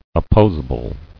[op·pos·a·ble]